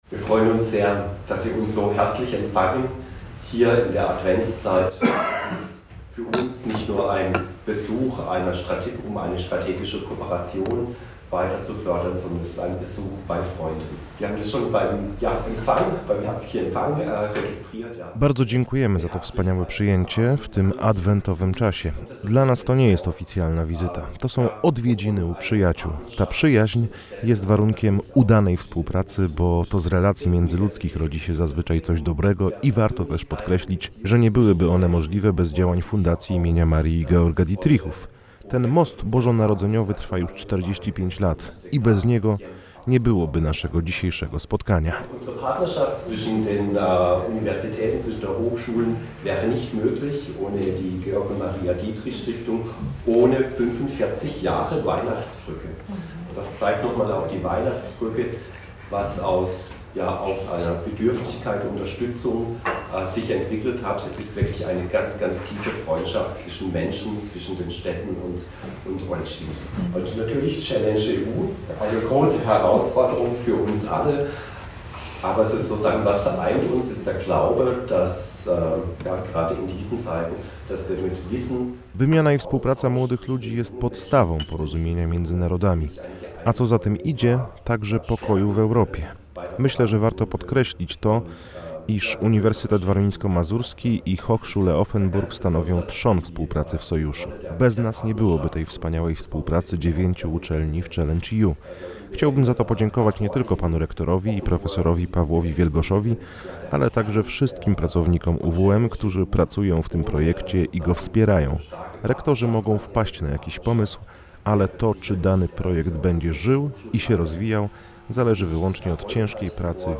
Posłuchajcie relacji z kolejnego spotkanie, które przed laty określono mianem Mostu Bożonarodzeniowego.